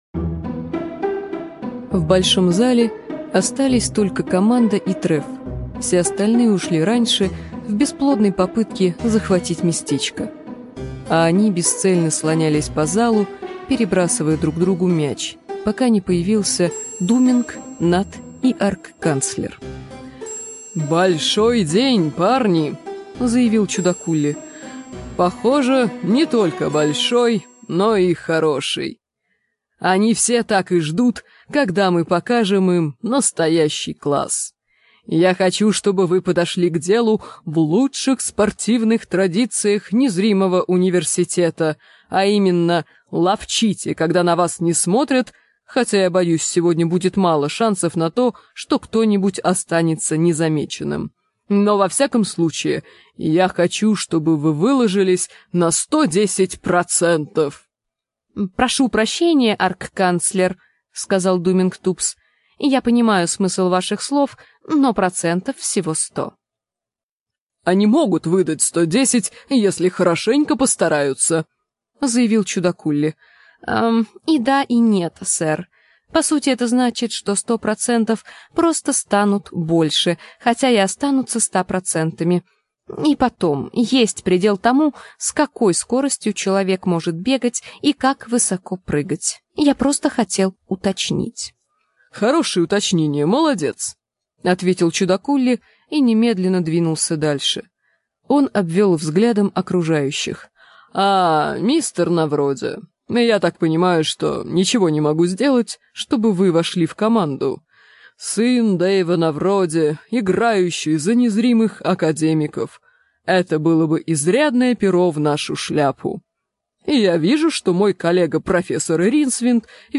Аудиокнига Незримые академики
Качество озвучивания весьма высокое.